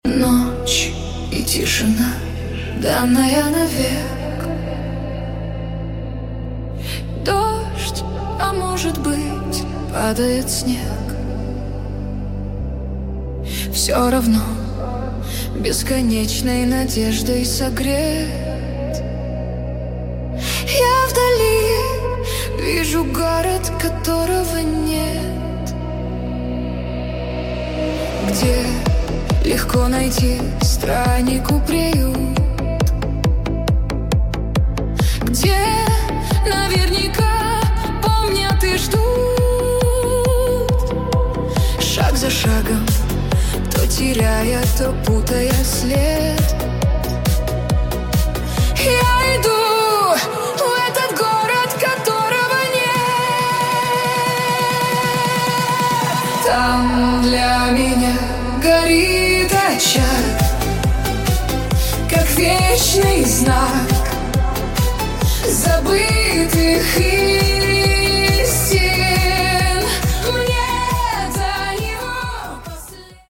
В исполнении девушки